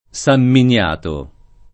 Sam minL#to] top.